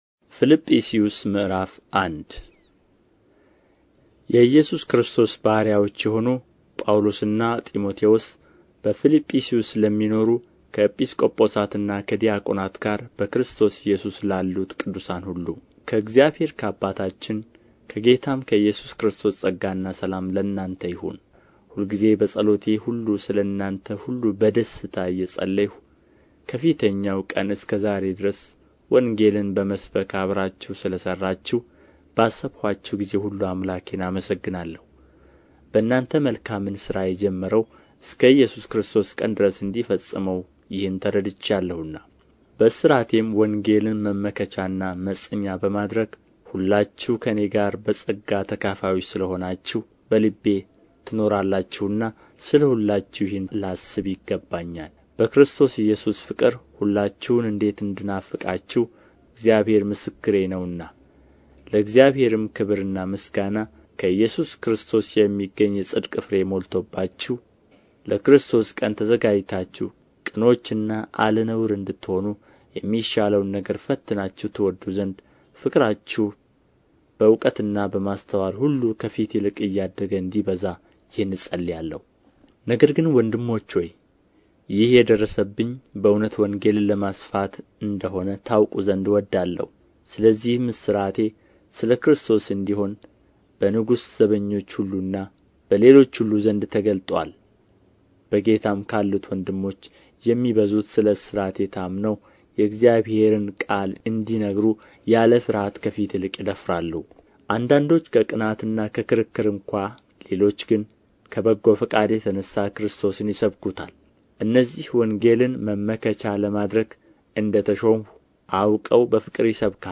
ምዕራፍ 1 ንባብ